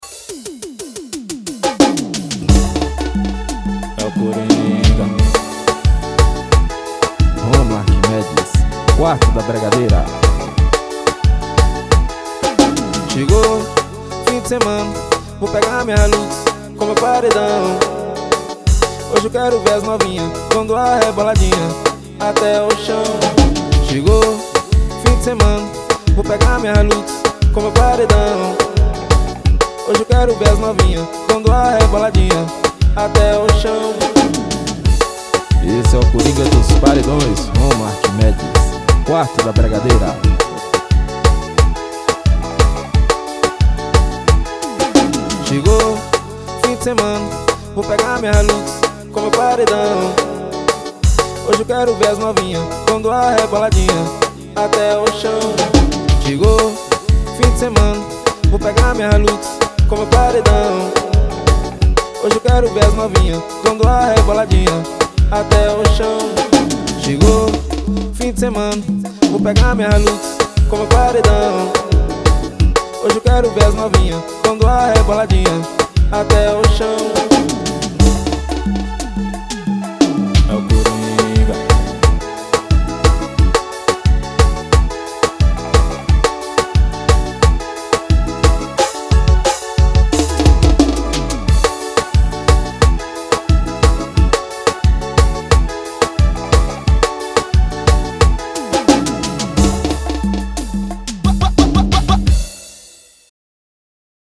EstiloBregadeira